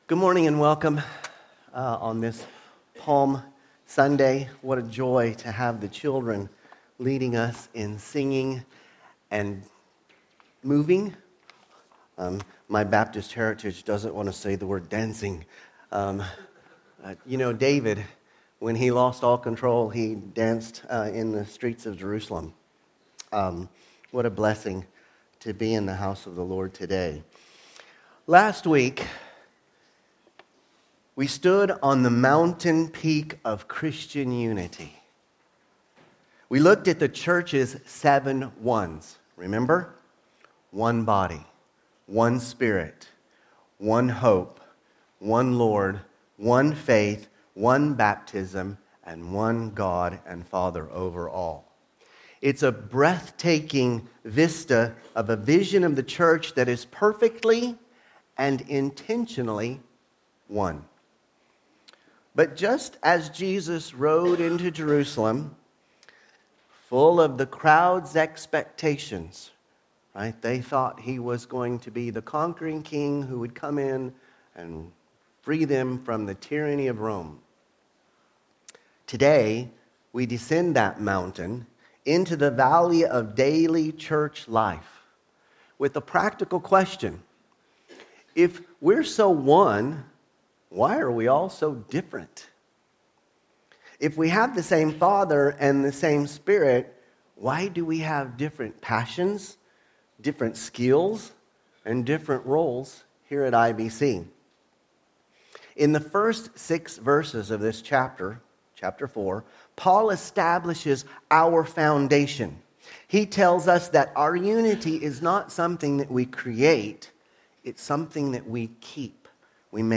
Sermons – Immanuel Baptist Church | Madrid
From Series: Sunday Service